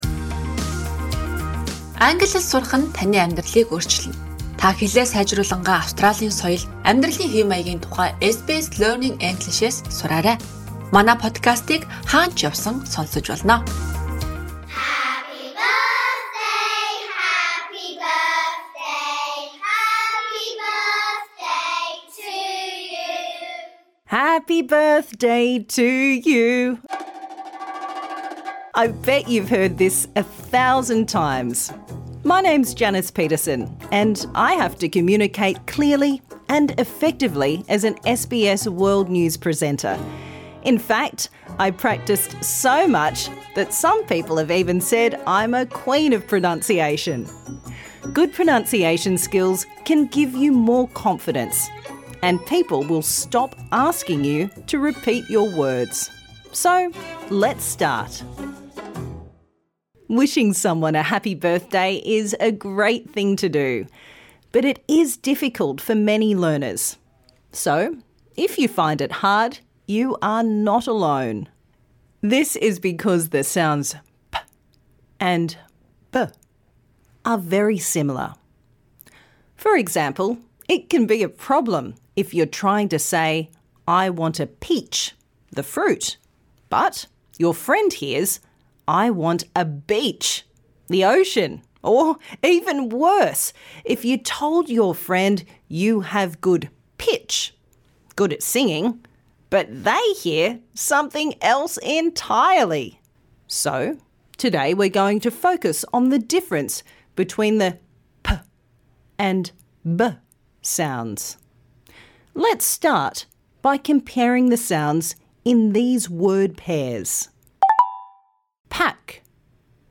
This lesson suits all learners at all levels.